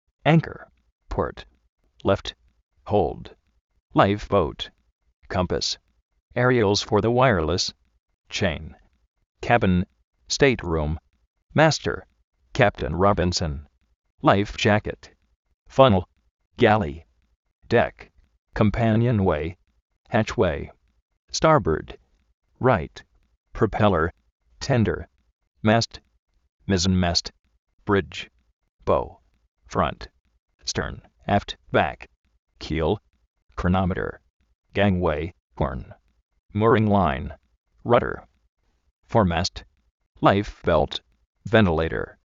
Vocabulario en ingles, diccionarios de ingles sonoros, con sonido, parlantes, curso de ingles gratis
ánkor
jóuld
láif-bóut
kómpas
chéin
kébin, stéit-rúm
stárbord (ráit)
propéler